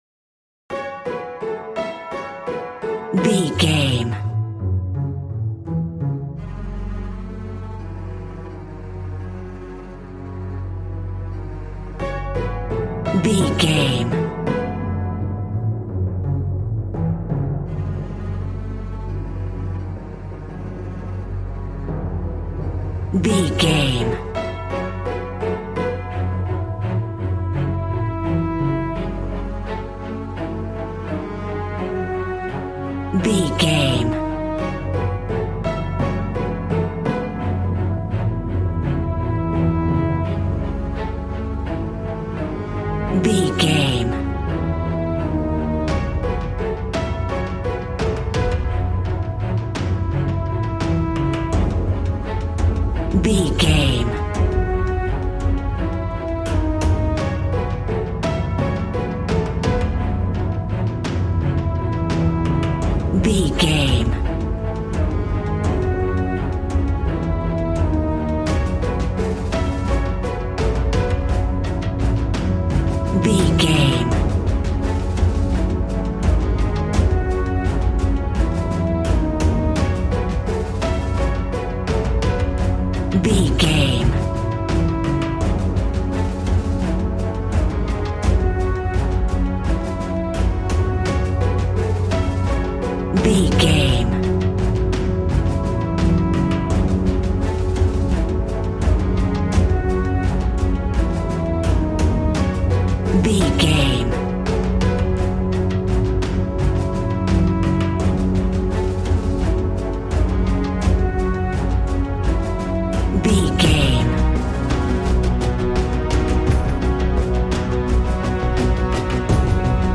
Epic / Action
Fast paced
In-crescendo
Uplifting
Aeolian/Minor
A♭
powerful
strings
brass
percussion
synthesiser